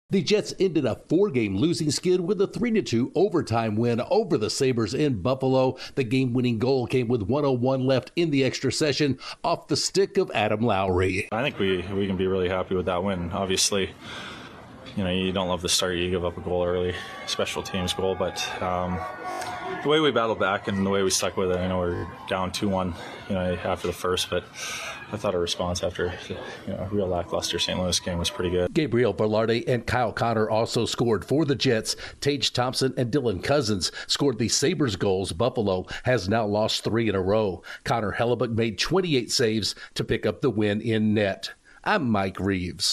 The Jets ended their losing streak with a win in Buffalo. Correspondent